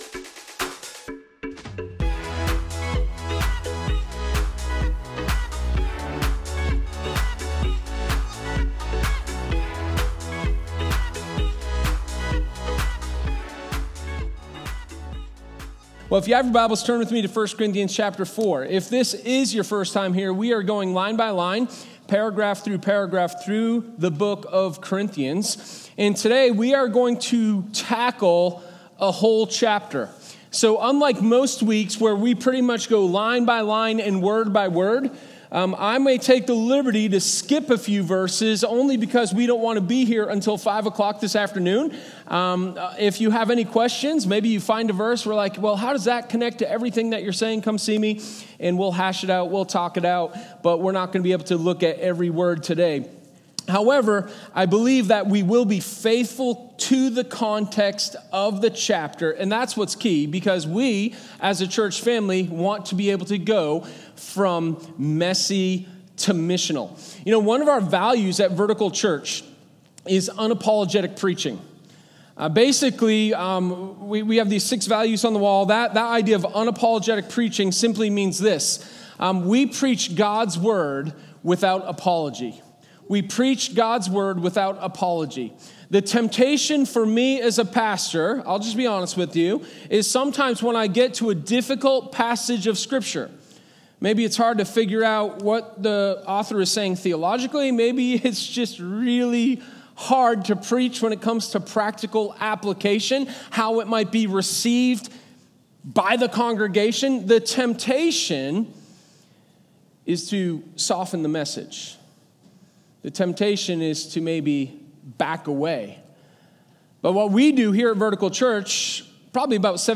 Sermon11_21.m4a